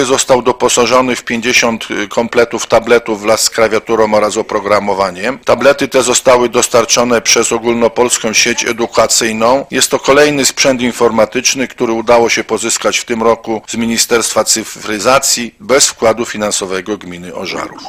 Mówi wiceburmistrz Paweł Rędziak: